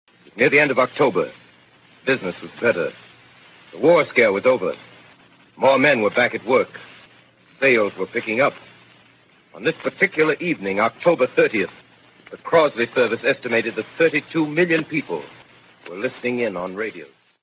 Opening speech of Orson Welles' War of the Worlds. Notice the precise exposition of the circumstances of the broadcast.